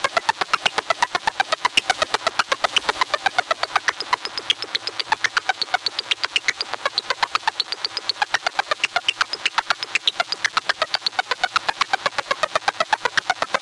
Bursting
analog analog-synth analog-synthesizer background burst bursting effect electronic sound effect free sound royalty free Sound Effects